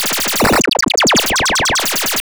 Glitch FX 37.wav